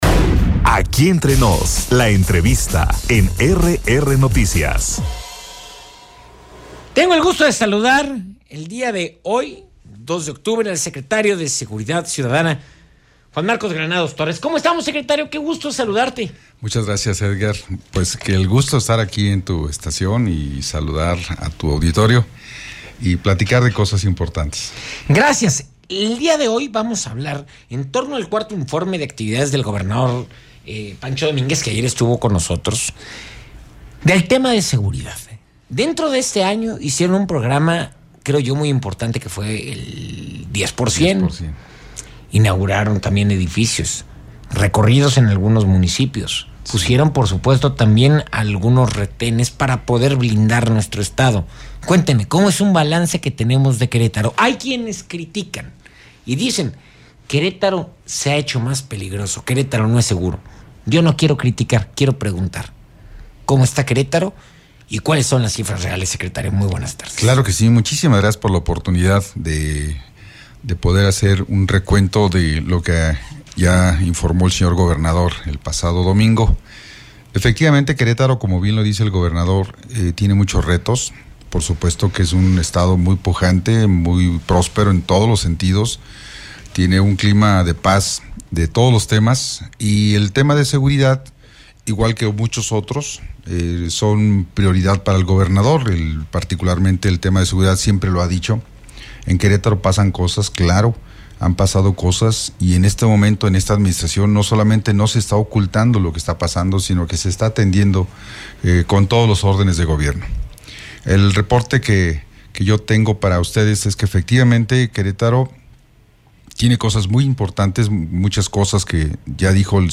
ENTREVISTA-TITULAR-DE-LA-SSC-JUAN-MARCOS-GRANADOS-TORRES.mp3